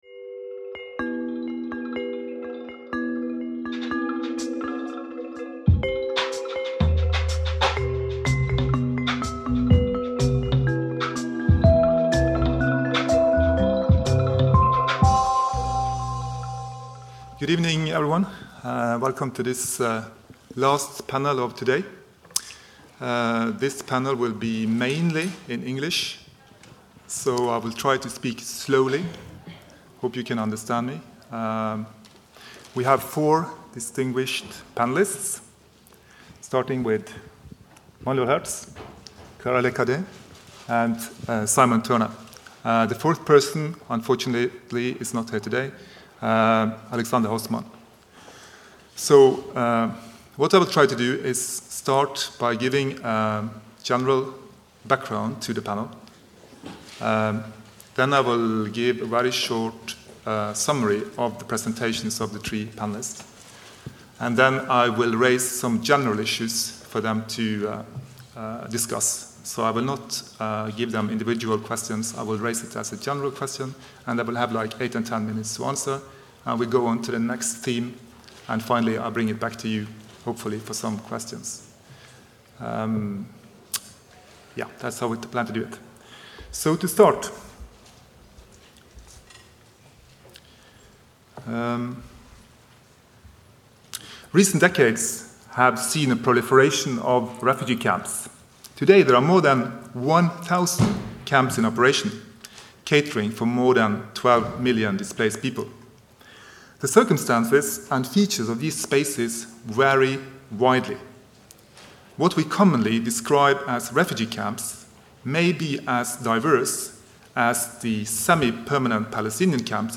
Il s’agit d’observer comment cette ambigüité se manifeste, s’exprime et est tolérée ou non. Discussion